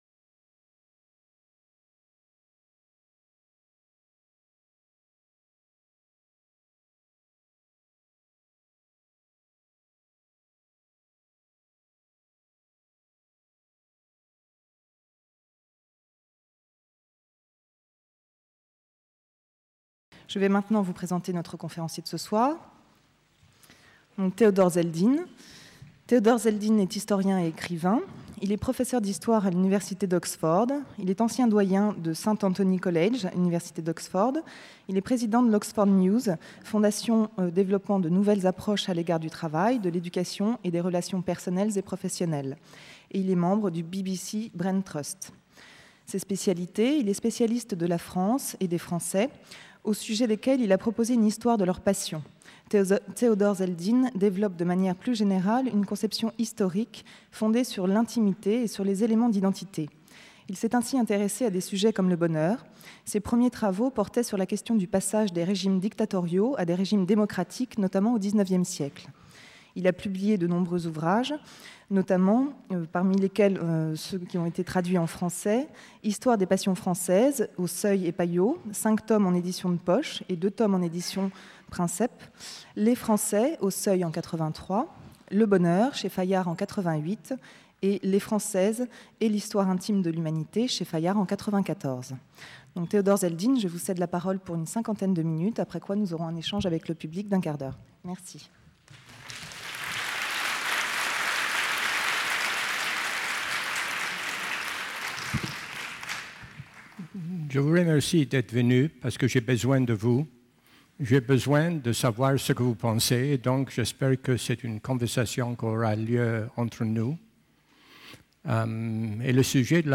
La conférence de l'Université de tous les savoirs du jeudi 6 novembre 2003, par Théodore Zeldin, historien et écrivain.